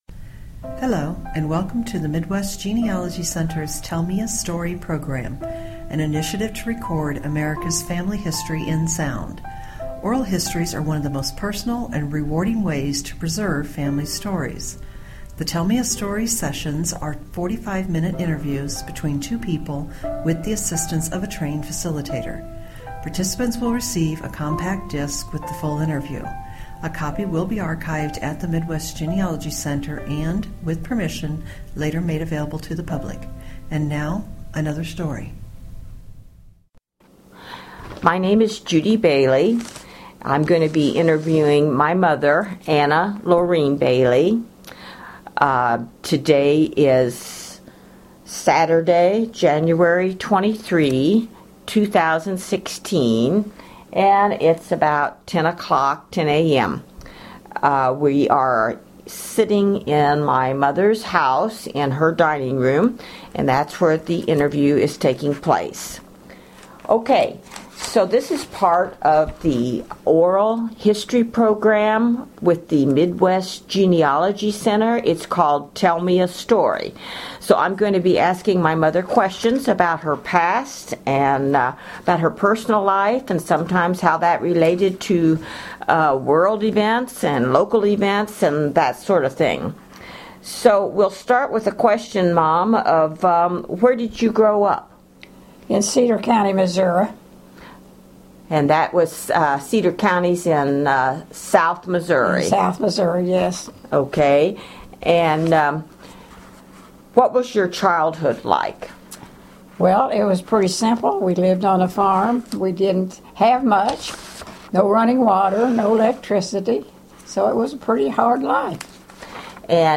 Oral Interview